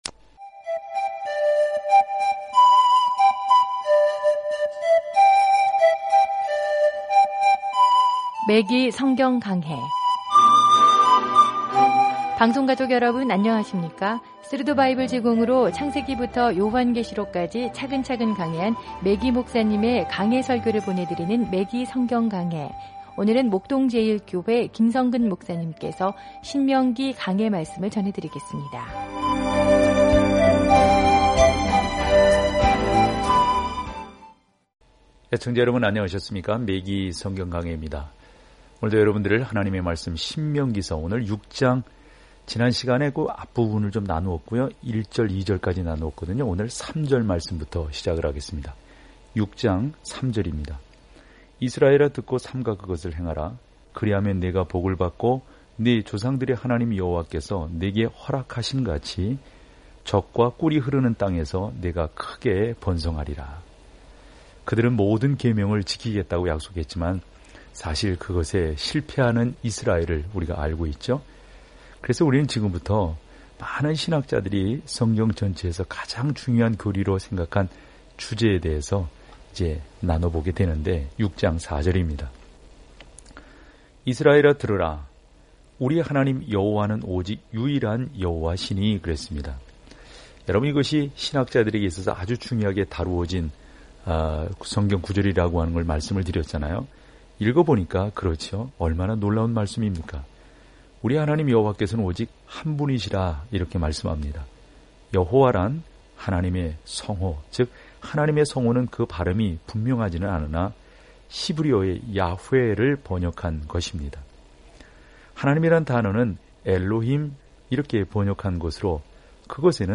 말씀 신명기 6:3-23 6 묵상 계획 시작 8 묵상 소개 신명기는 하나님의 선한 율법을 요약하고 순종이 그분의 사랑에 대한 우리의 반응이라고 가르칩니다. 오디오 공부를 듣고 하나님의 말씀에서 선택한 구절을 읽으면서 매일 신명기를 여행하세요.